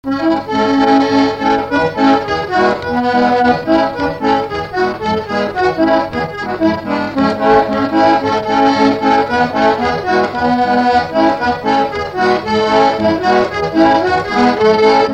Instrumental
danse : fox-trot
Pièce musicale inédite